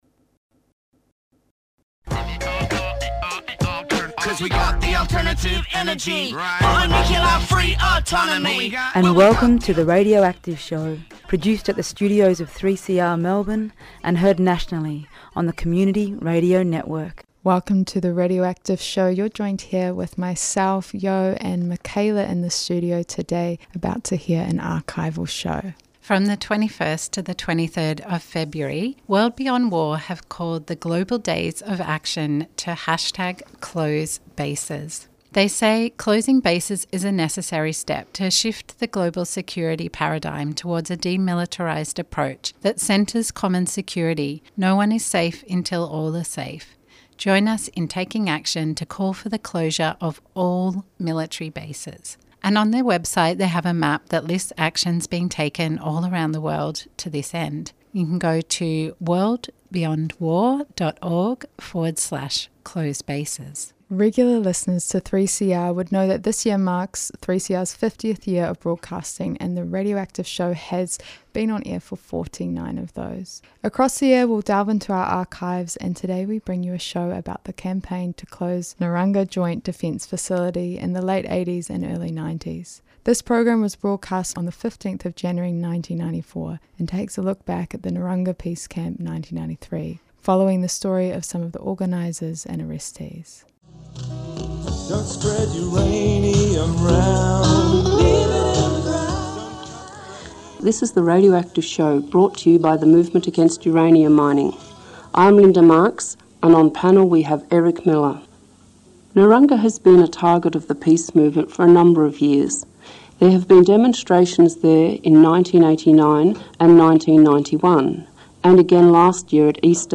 A special edition Radioactive Show this week for International Women's Day, honoring the women at the forefront of many campaigns to stop the nuclear industry here in this country but also globally.